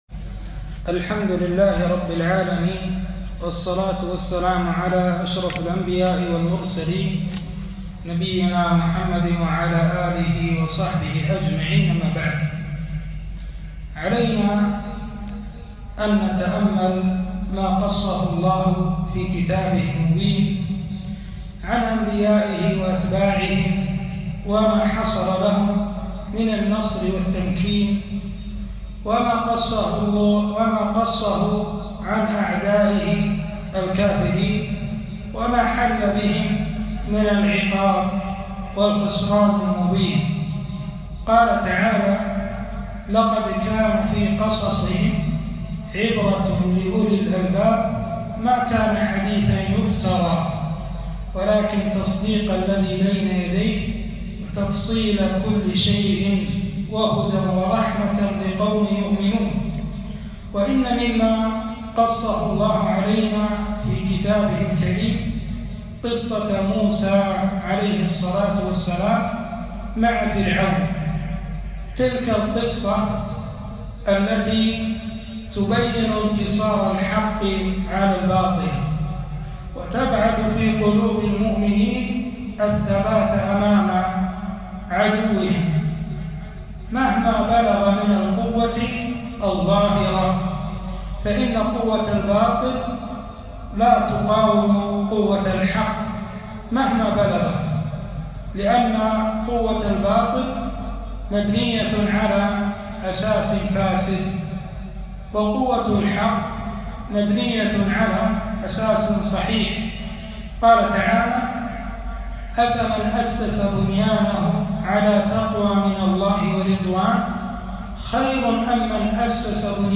كلمة - الحكمة من صيام يوم عاشوراء